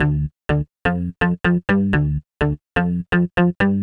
cch_bass_loop_pull_125_F.wav